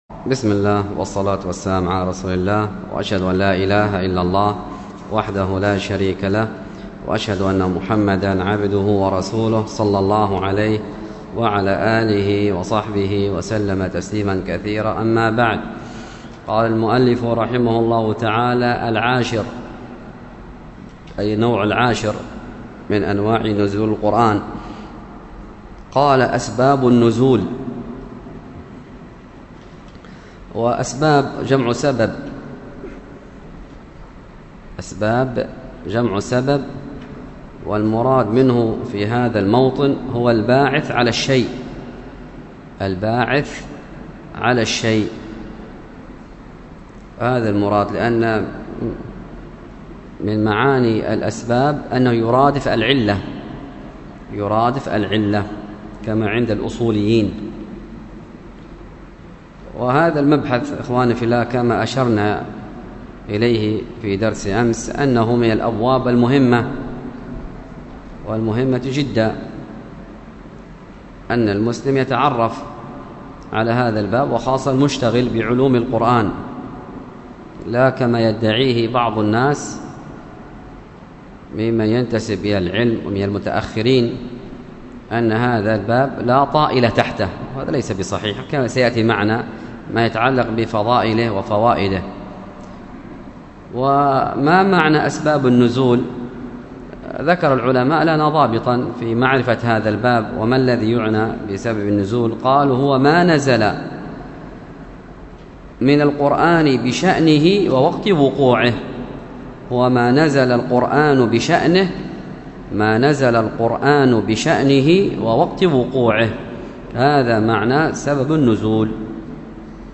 الدرس في شرح المذكرة - مقرر السنة الأولى 16، ألقاها